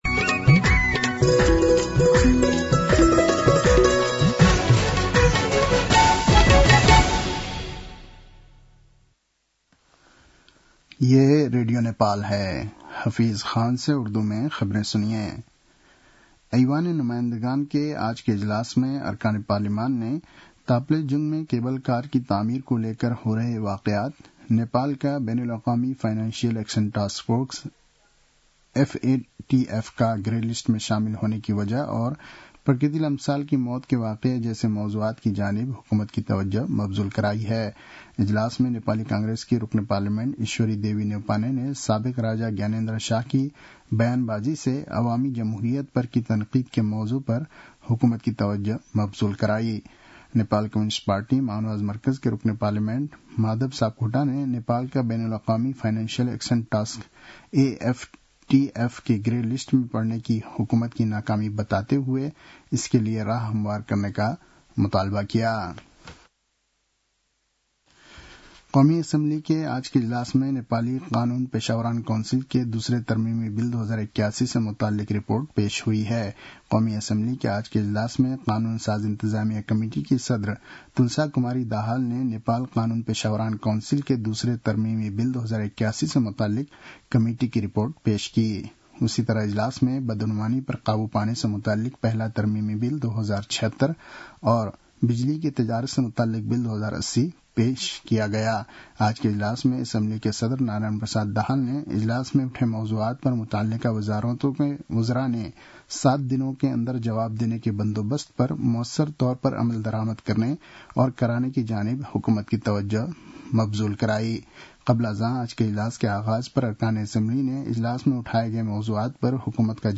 उर्दु भाषामा समाचार : १२ फागुन , २०८१